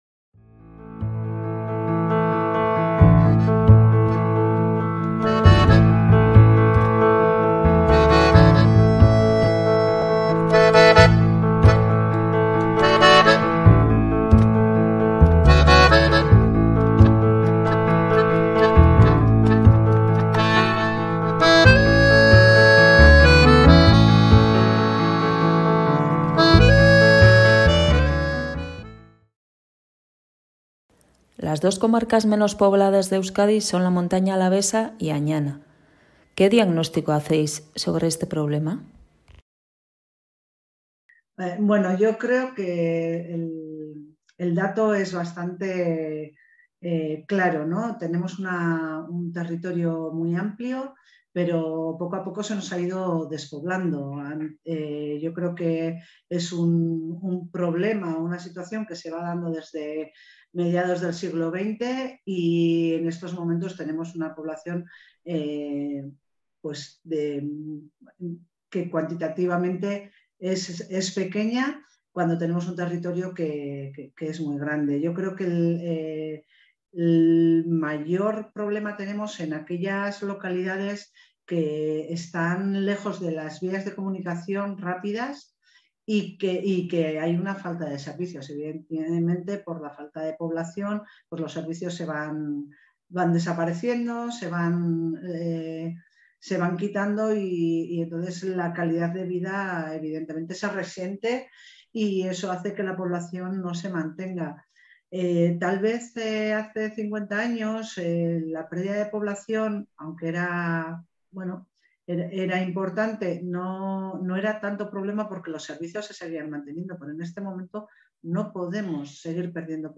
El diálogo del mes se inscribe en las jornadas e iniciativas que este otoño se vienen desarrollando en Álava.